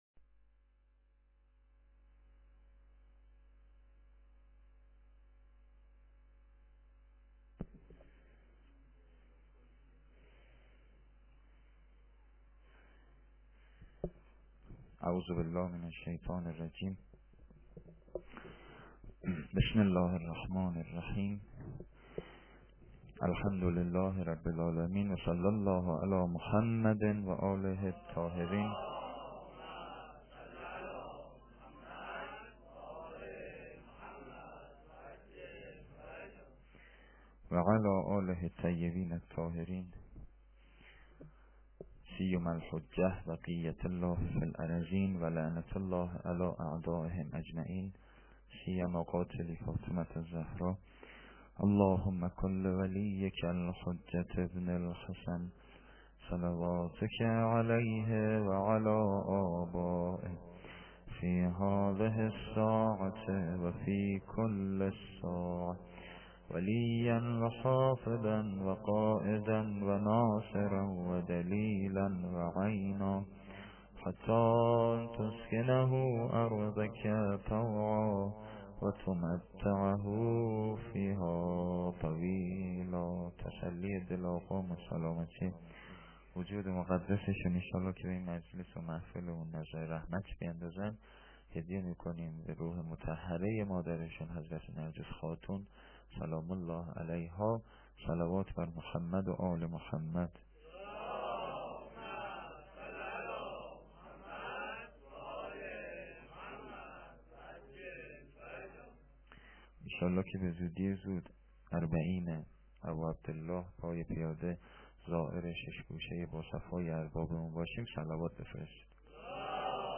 sokhanrani-9.lite.mp3